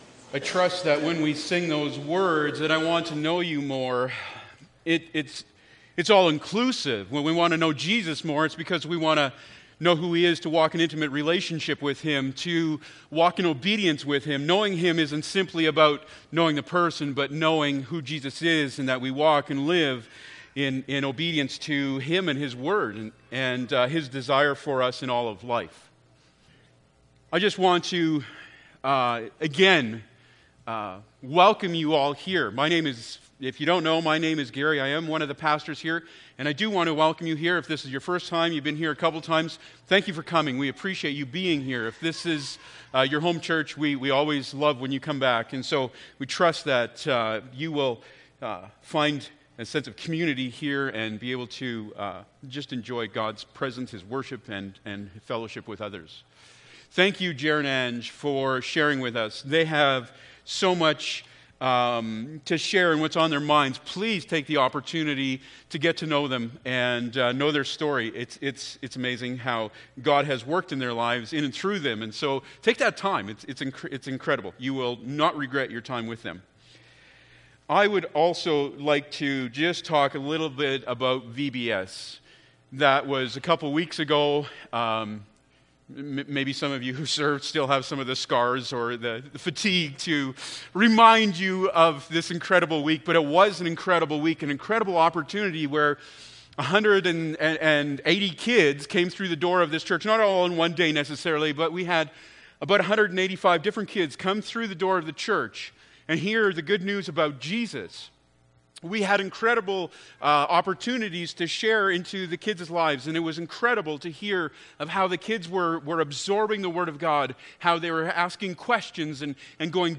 1-3 Service Type: Sunday Morning Bible Text